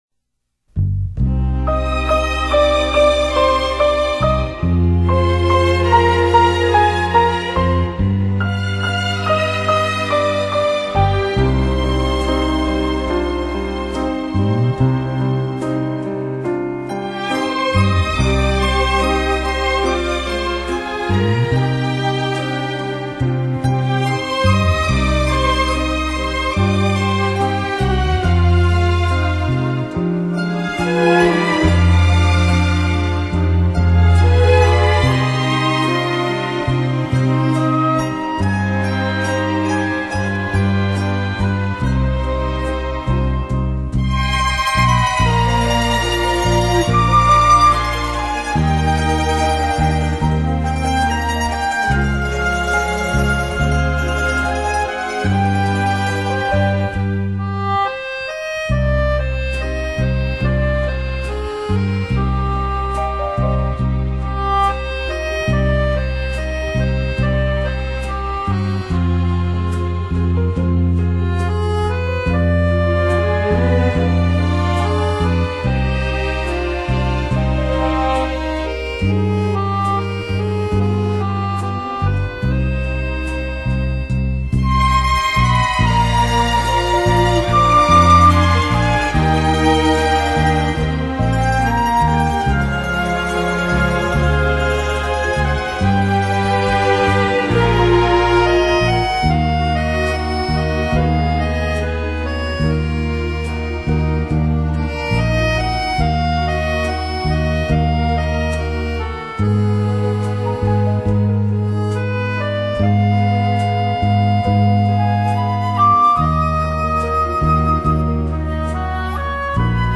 [light]小提琴发烧天碟[/light]